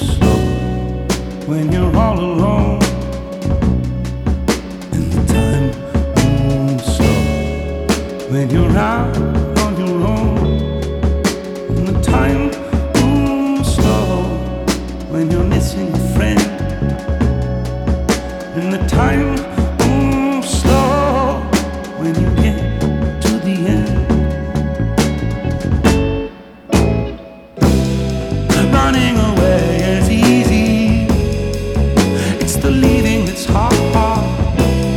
Жанр: Хип-Хоп / Рэп / R&B / Электроника / Джаз / Соул / Фанк